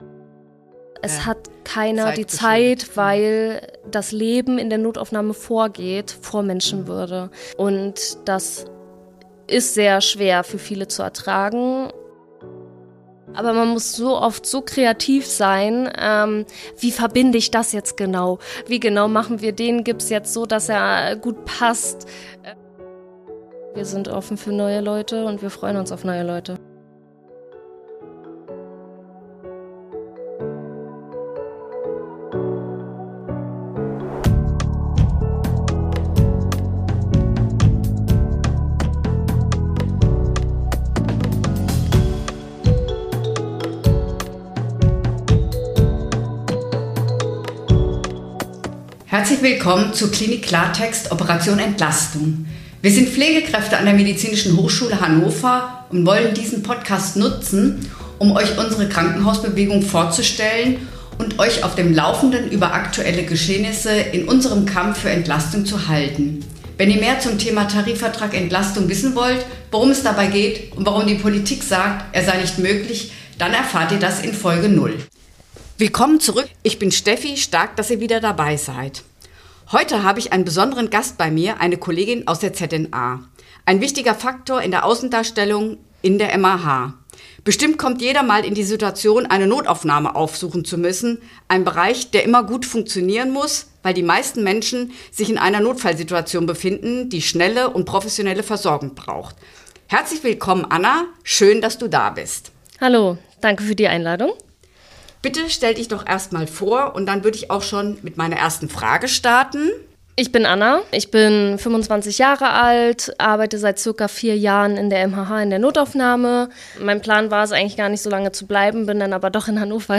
Zentrale Notaufnahme – Ein Interview